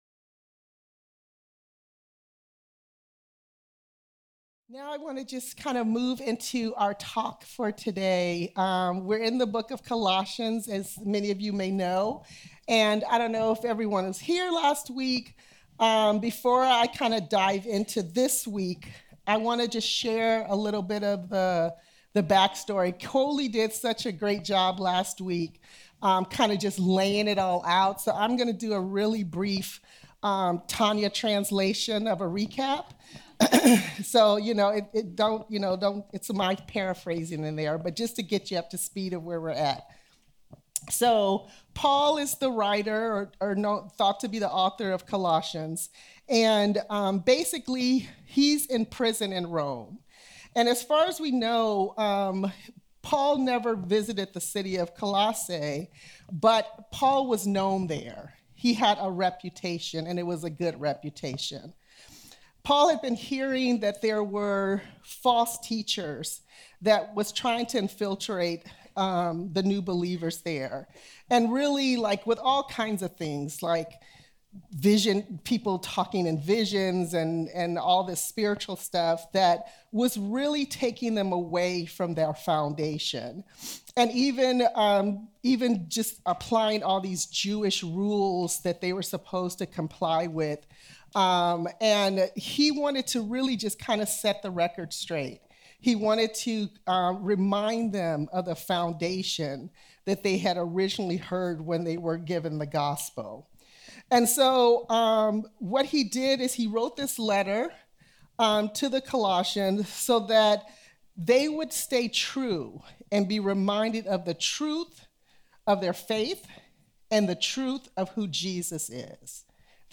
Audio Messages From The Women's Ministry at Christian Assembly Church in Eagle Rock, California